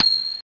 ting.mp3